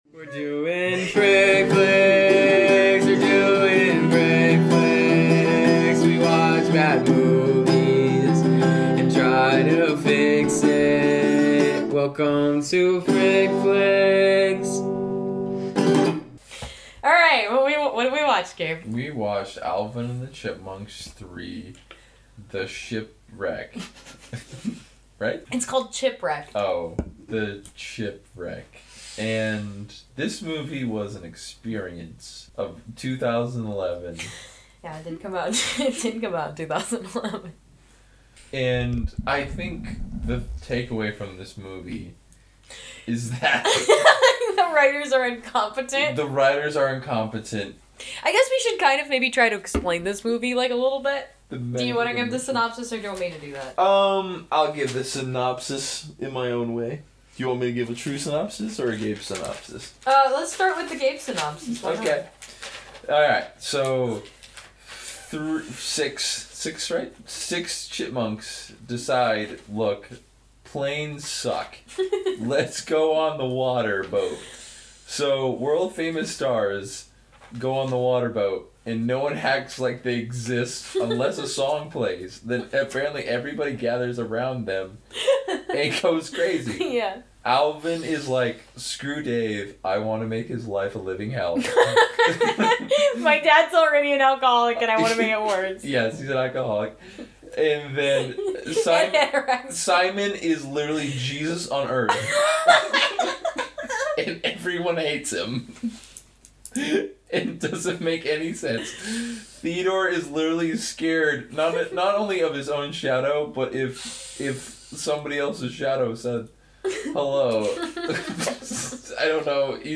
There will be laughing, crying, and definitely taking the potential of cartoon chipmunks way too seriously.